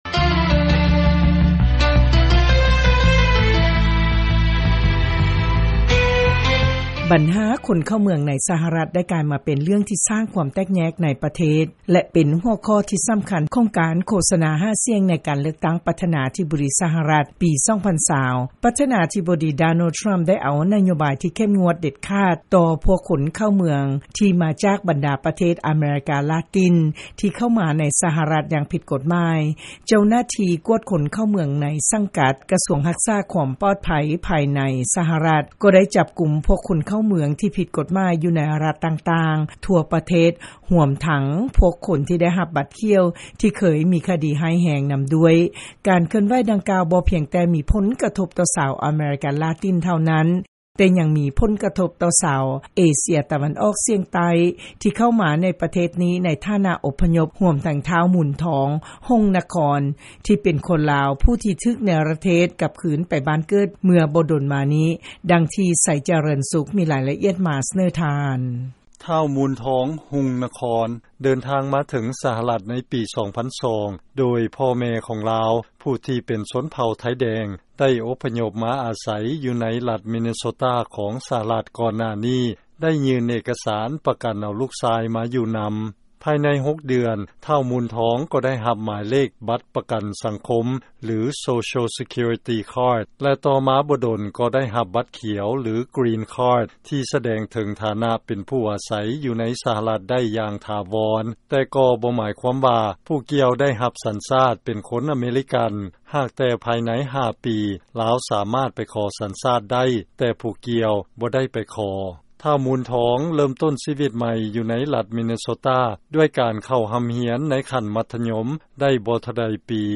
ເຊີນຟັງລາຍງານ ຄົນລາວຜູ້ນຶ່ງ ທີ່ໄດ້ເຂົ້າມາຢູ່ໃນ ສະຫະລັດ ແລະໄດ້ຮັບບັດຂຽວ ຖືກເນລະເທດ ກັບຄືນໄປລາວ